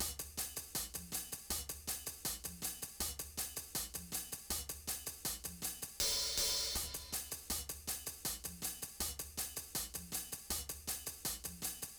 WASTED LOVE DRUM LOOP 160 BPM.wav